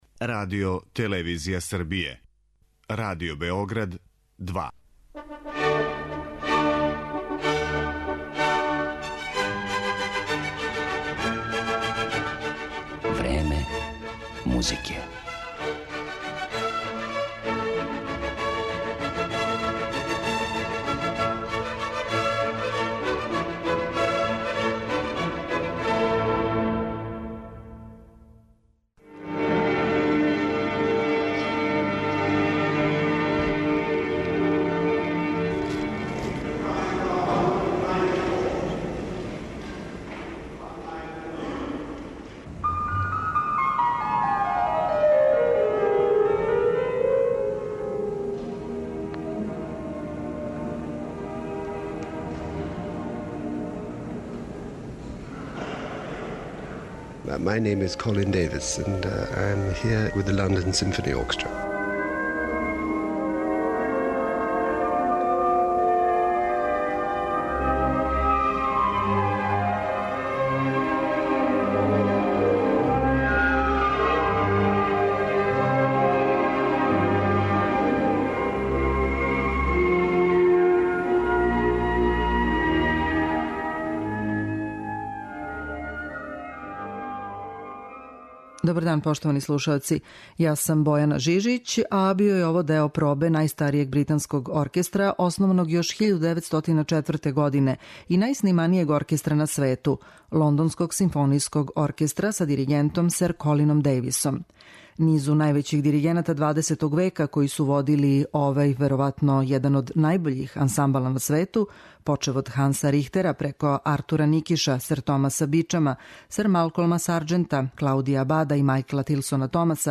Једном од највећих музичара нашег доба и дугогодишњем руководиоцу Лондонског симфонијског оркестра, британском диригенту сер Колину Дејвису, посвећена је данашња емисија у којој ћете моћи да чујете и интервју са овим славним уметником.